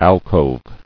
[al·cove]